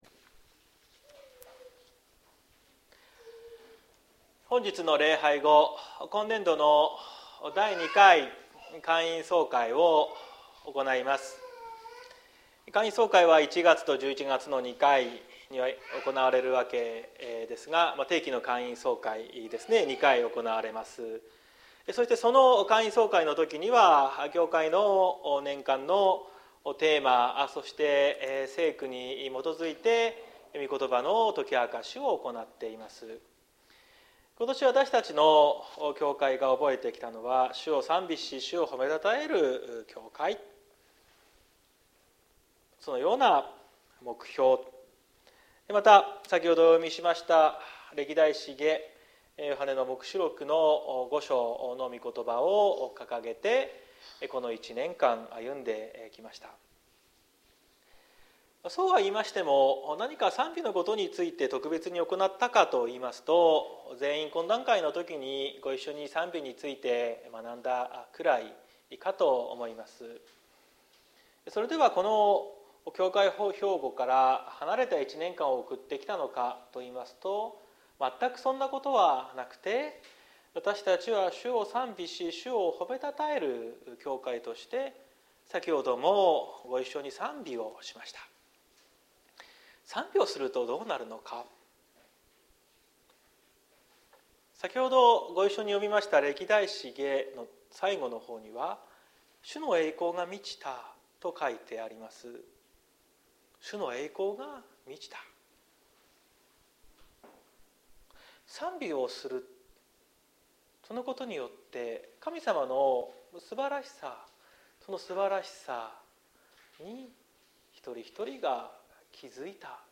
2023年11月26日朝の礼拝「主を賛美する教会」綱島教会
説教アーカイブ。